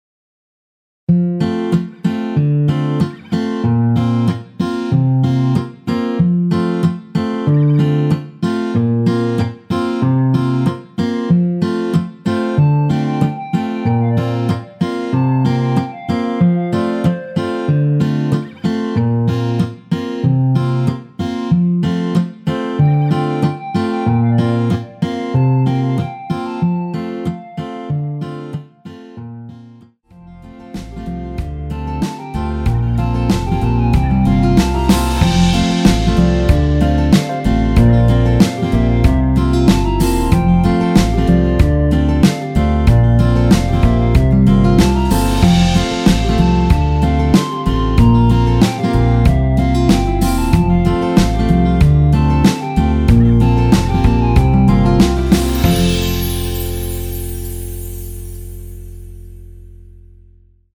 원키에서(+6)올린 멜로디 포함된 MR입니다.
앞부분30초, 뒷부분30초씩 편집해서 올려 드리고 있습니다.
중간에 음이 끈어지고 다시 나오는 이유는